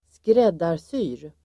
Uttal: [²skr'ed:ar_sy:r]